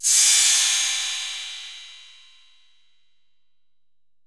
808LP49CYM.wav